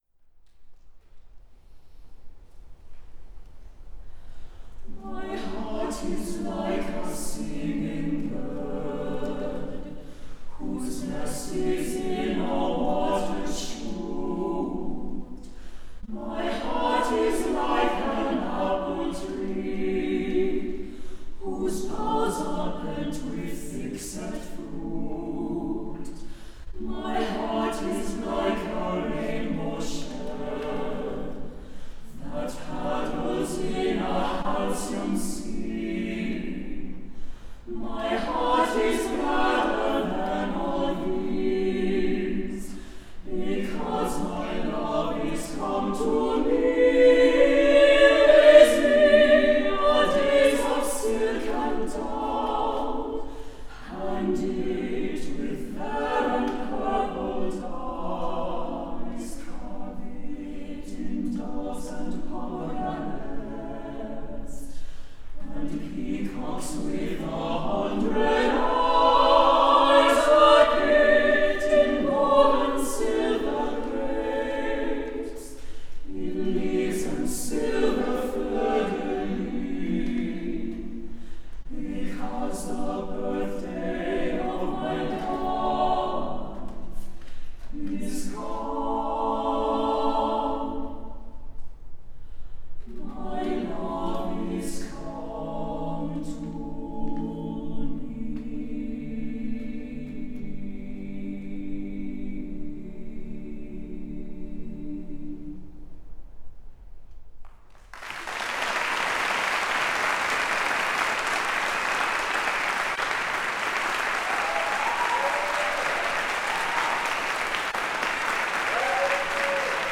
for TTBB a cappella